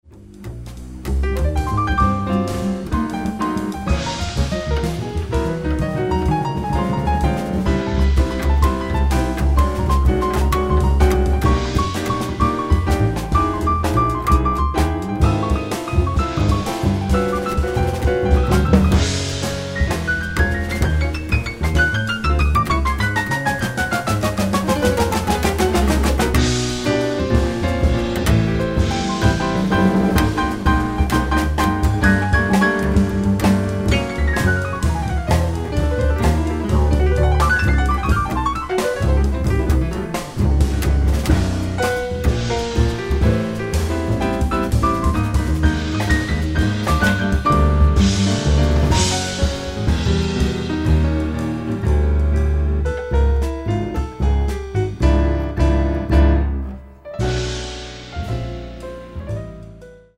piano trio
is a dark cinematic waltz